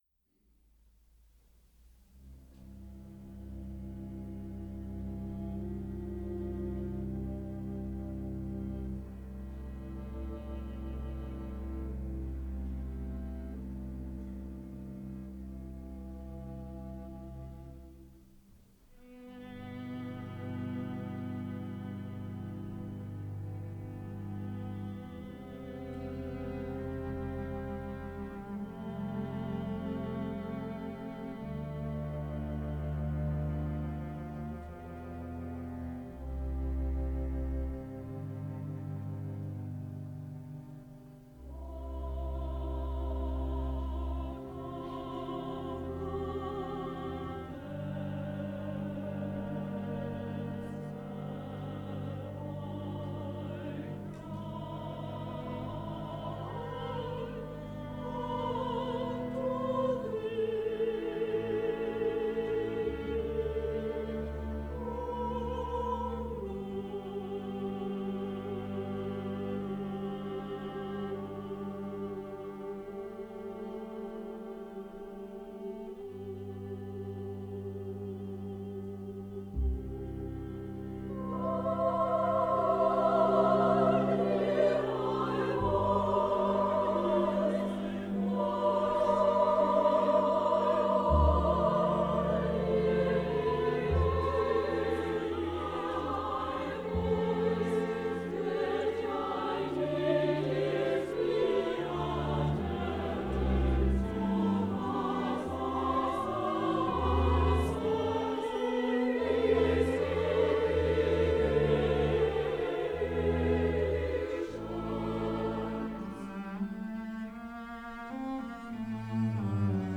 Listen to historic chamber music recordings online as heard at Vermont's Marlboro Music Festival, classical music's most coveted retreat since 1951.
Psalm 130 for Women’s Chorus, Two Violas, Two Cellos and Double Bass, Op. 61c